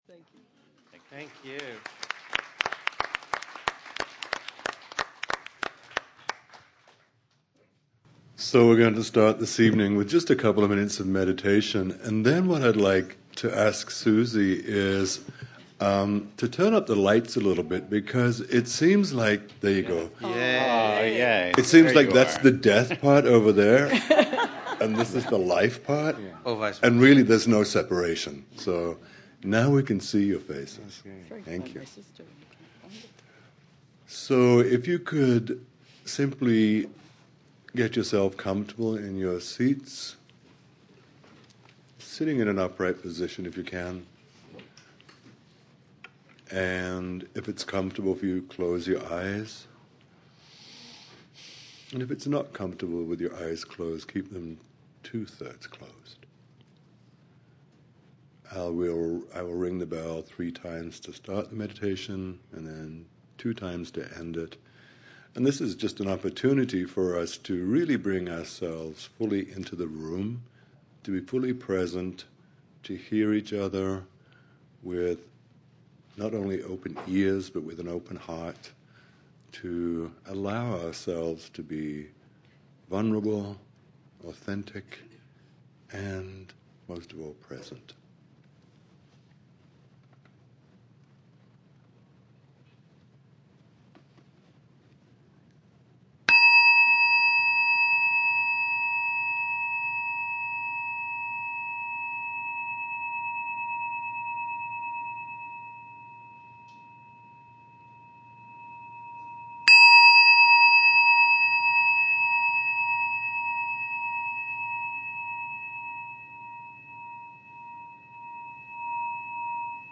An intimate conversation about experiences with giving end-of-life care and the many lessons it brings for caregivers.
Listen to the complete audio from the event below.